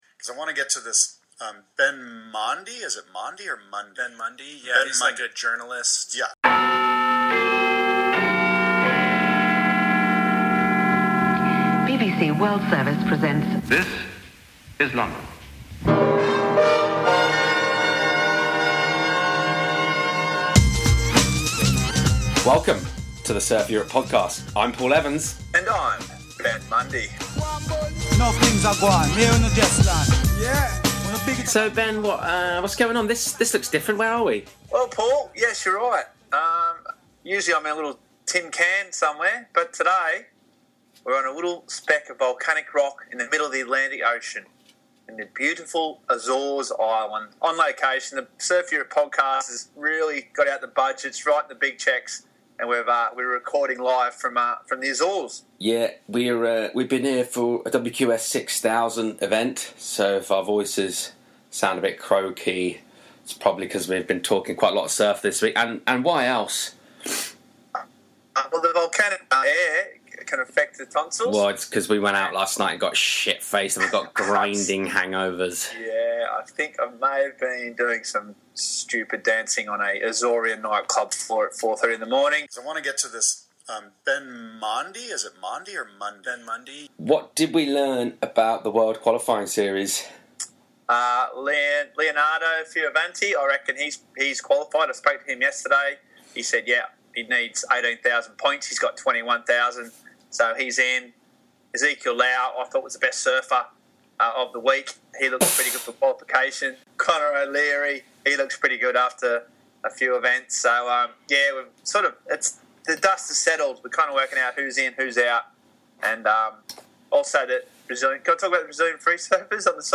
Recorded in the Mid-Atlantic!
Episode 4 comes live and direct from the Mid Atlantic!